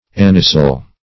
Meaning of anisyl. anisyl synonyms, pronunciation, spelling and more from Free Dictionary.
Search Result for " anisyl" : The Collaborative International Dictionary of English v.0.48: Anisyl \An"i*syl\, n. (Org.